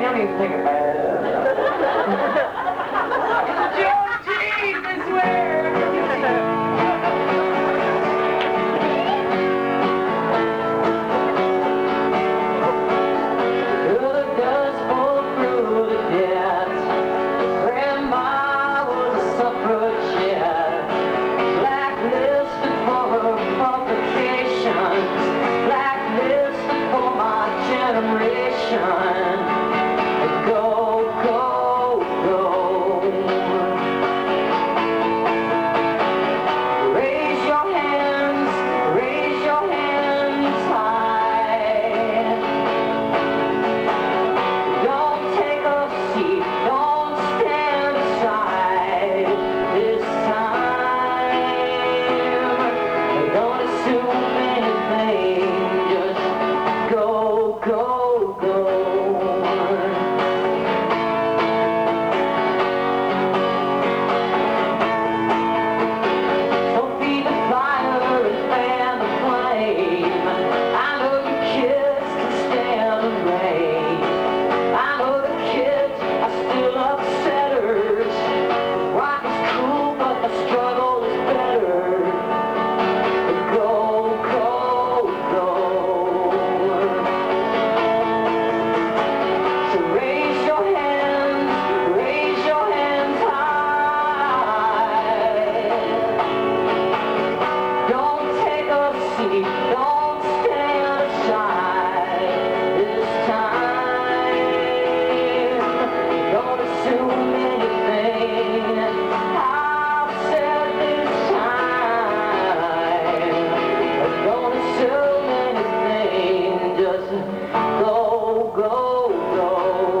acoustic) (first set of two at writer's night